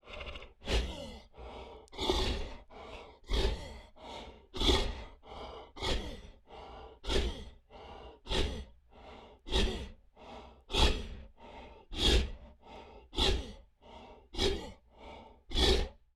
sfx_猪头喘气.wav